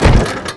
compress_truck_3.wav